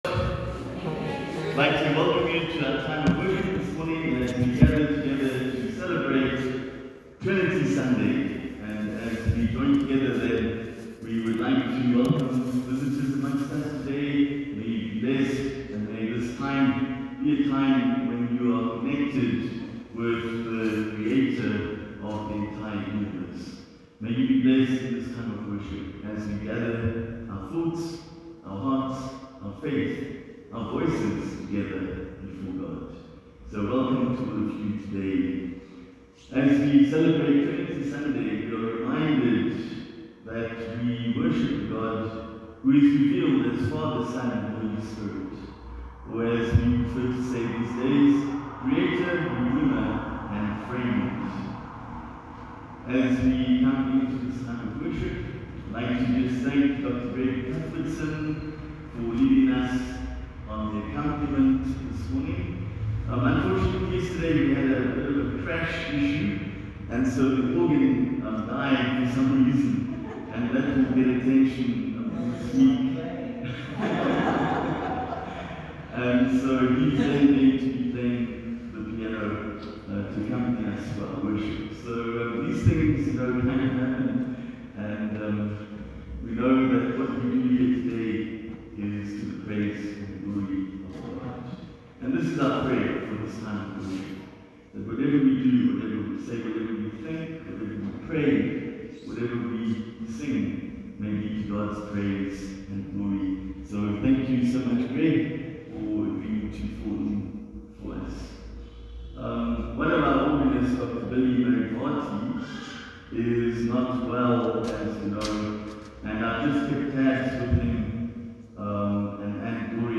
Sunday Service – 30 May 2021